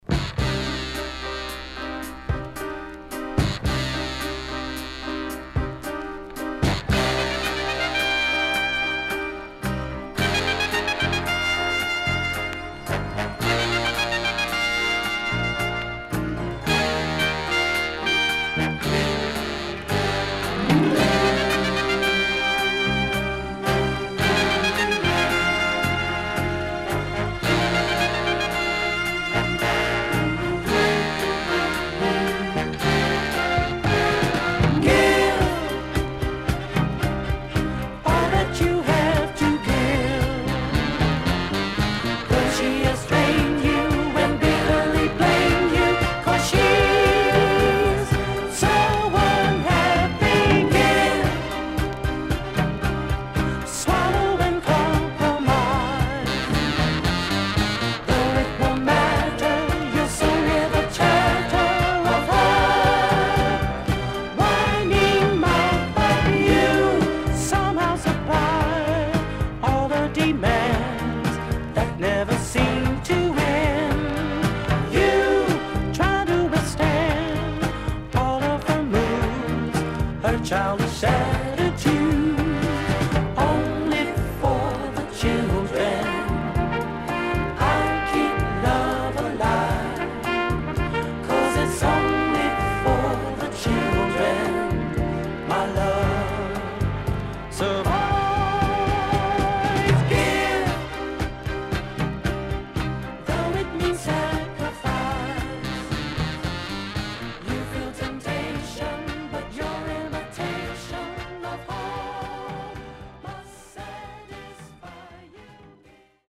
HOME > SOUL / OTHERS
SIDE A:少しチリノイズ入ります。